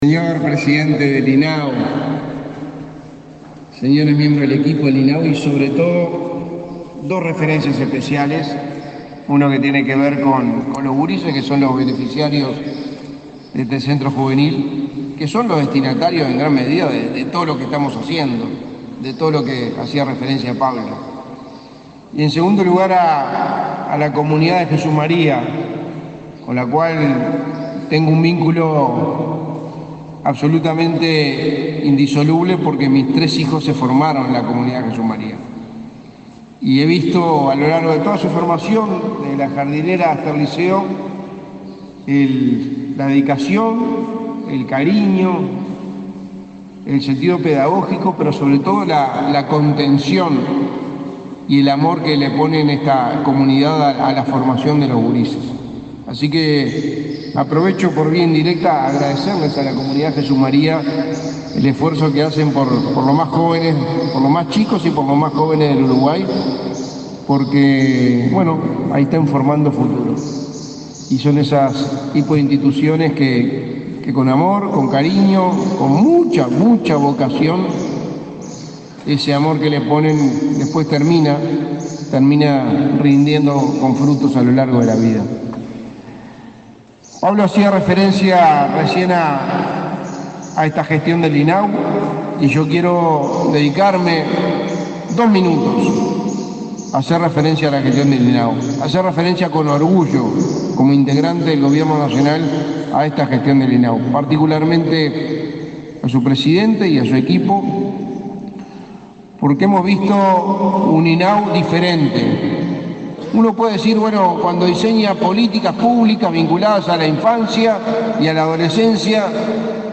Palabras del secretario de Presidencia, Álvaro Delgado
Palabras del secretario de Presidencia, Álvaro Delgado 27/09/2023 Compartir Facebook X Copiar enlace WhatsApp LinkedIn Este miércoles 27, el secretario de la Presidencia, Álvaro Delgado, participó en la inauguración de un centro juvenil del Instituto del Niño y el Adolescente del Uruguay (INAU), en la localidad de Cardona, departamento de Soriano.